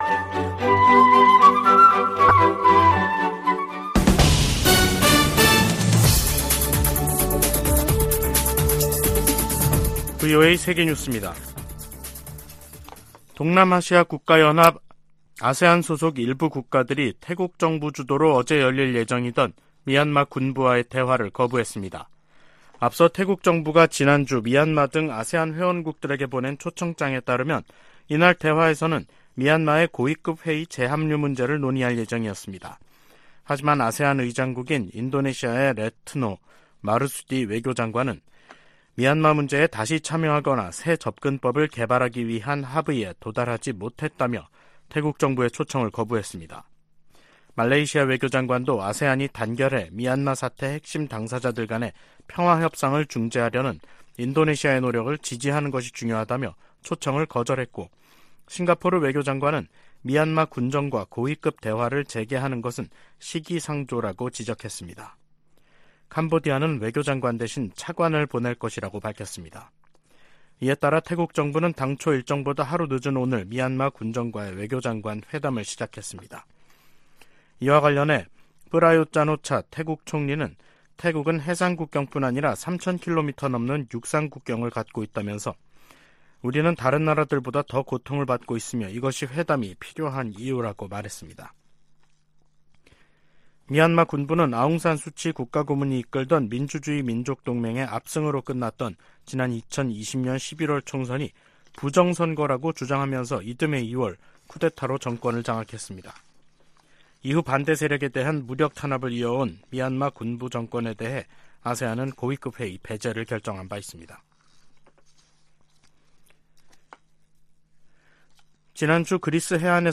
VOA 한국어 간판 뉴스 프로그램 '뉴스 투데이', 2023년 6월 19일 2부 방송입니다. 미국의 핵 추진 순항미사일 잠수함의 한국 입항과 관련해 미국 내 전문가들은 방위 공약을 확인하는 조치로 해석했습니다. 북한은 노동당 전원회의에서 군사정찰위성 발사 실패를 가장 엄중한 결함으로 지목하고 이른 시일 내 재발사하겠다고 밝혔습니다. 미 하원의장이 주한 중국대사의 '베팅 발언'에 대해 잘못된 것이라고 지적했습니다.